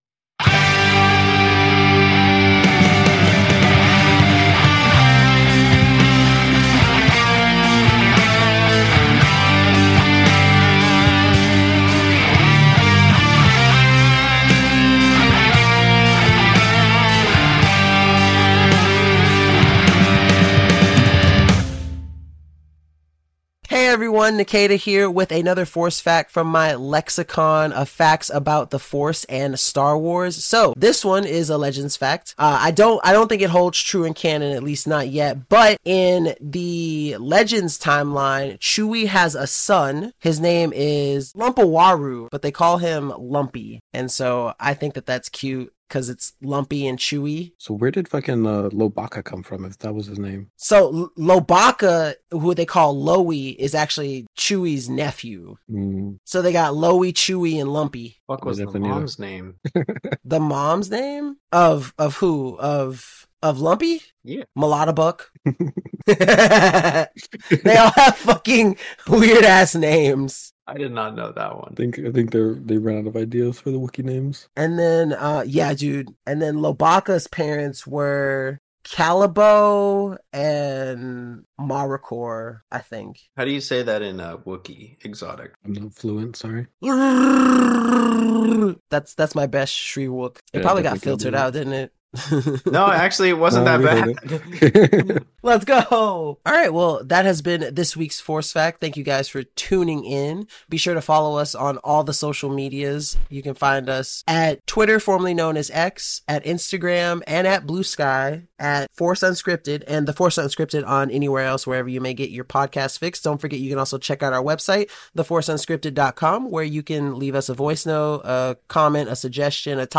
Four friends explore, discover and unravel the mysteries of the Star Wars universe, diving into both the Canon and the Legends timeline to give you all the Star Wars content you never knew you needed.